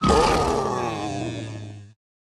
Minecraft Version Minecraft Version 25w18a Latest Release | Latest Snapshot 25w18a / assets / minecraft / sounds / mob / sniffer / death1.ogg Compare With Compare With Latest Release | Latest Snapshot
death1.ogg